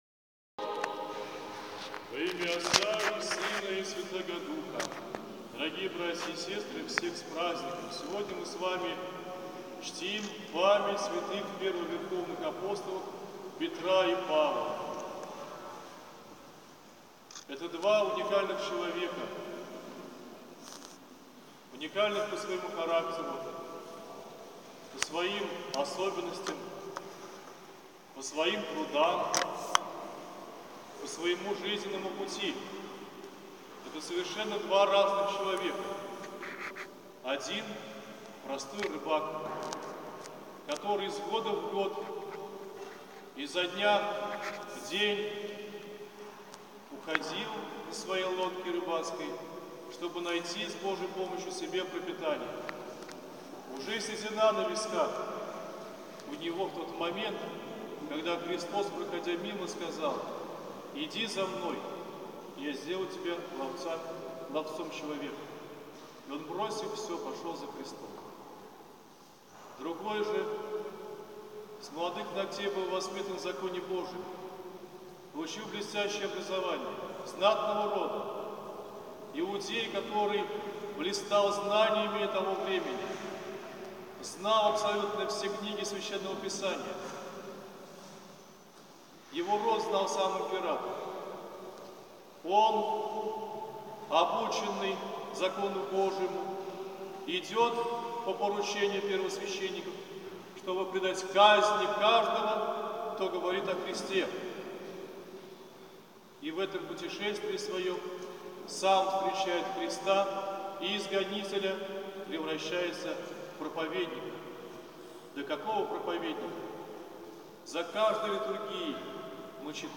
По окончании богослужения
обратился к верующим с пастырским словом